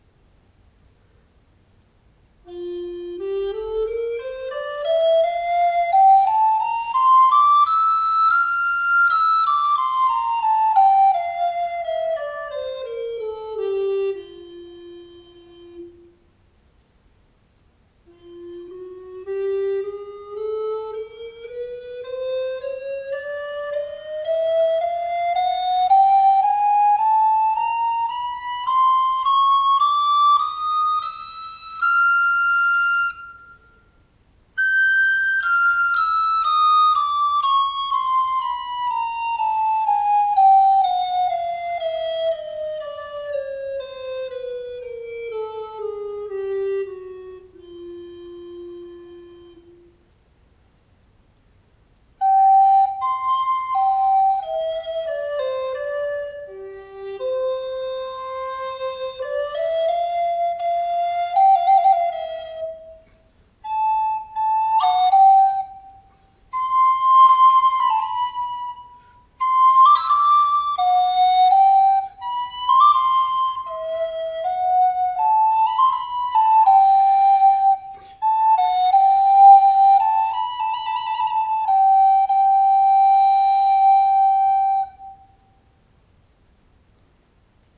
【材質】エボニー
エボニーの楽器は比重の高さが特徴ですが、アルト以上になると重くて持ちづらいというケースも。タケヤマのエボニーは（個体にもよりますが）比較的軽めで扱いやすいです。同じエボニーでも同時に選定したソプラノよりも息の抵抗感がやや強く、艶のある深みが感じられる音色。アンブシュアのコントロールにもしっかり反応してくれるフレキシビリティも備えています。独奏や、バロックの室内楽にピッタリでしょう。